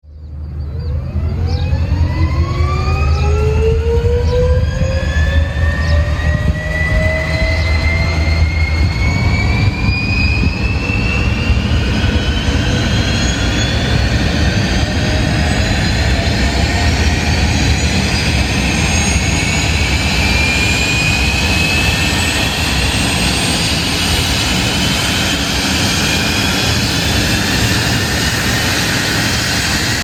Звуки турбины самолета
Быстрый запуск турбины и достижение пиковой мощности